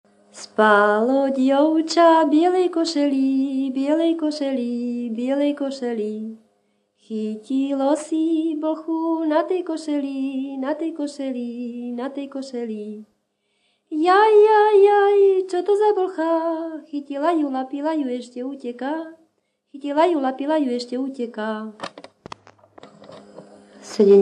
Popis sólo ženský spev bez hudobného sprievodu
Miesto záznamu Litava
Predmetová klasifikácia 11.7. Piesne pri tanci
Kľúčové slová ľudová pieseň